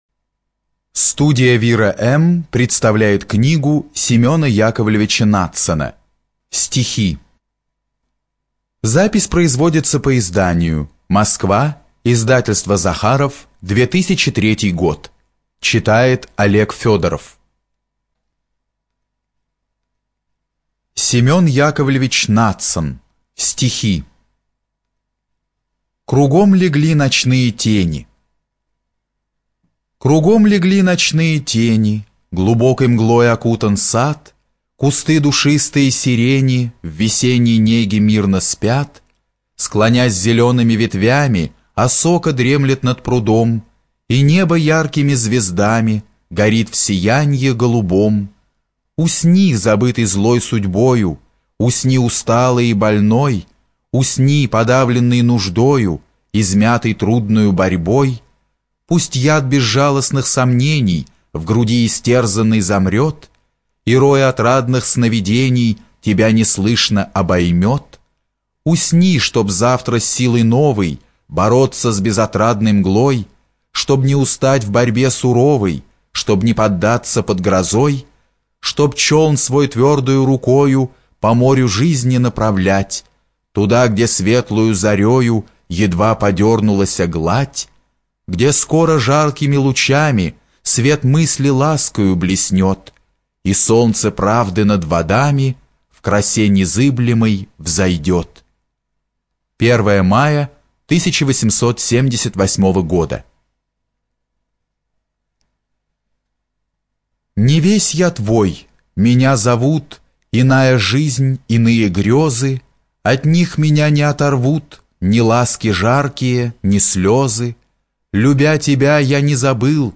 ЖанрПоэзия
Студия звукозаписиВира-М